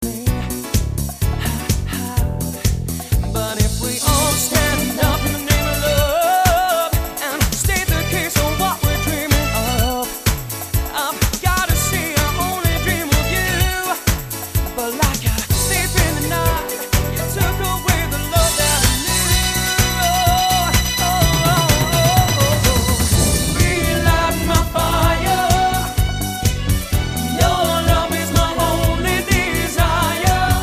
great vocal sound
still performing as a five piece with their very own Robbie.